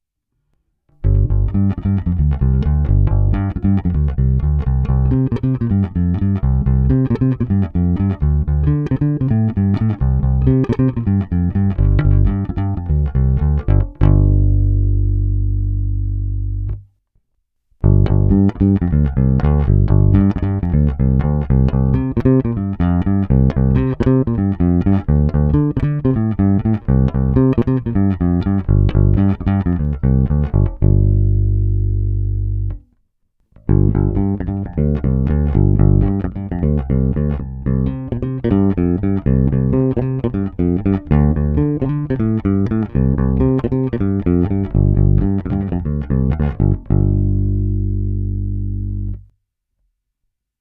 Zvuk je klasický pozdně padesátkový s bohatým spektrem výšek, agresívní, zvonivý s hutným basovým základem.
S oběma typy strun jsem provedl nahrávky rovnou do zvukové karty (není-li uvedeno jinak) a dále ponechal bez úprav, až na normalizaci samozřejmě.
Ve stejném pořadí jako výše – Flatwound